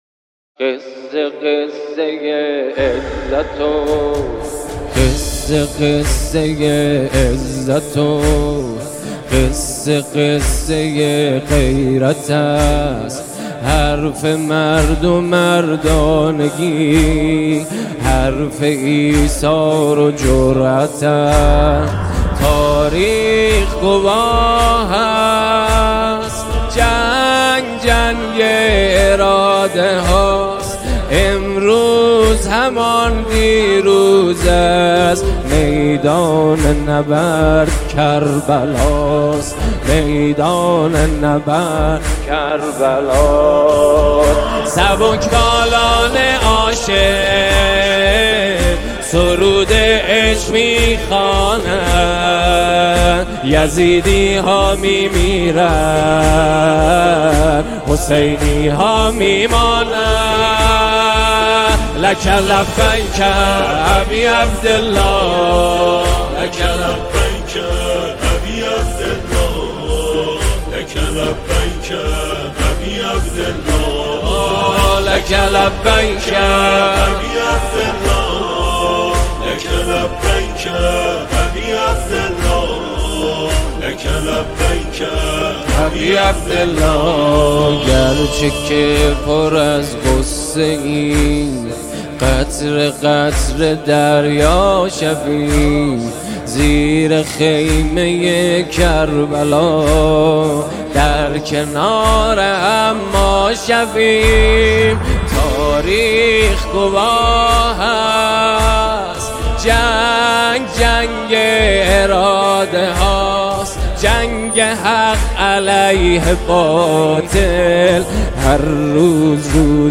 سرود
نماهنگ دلنشین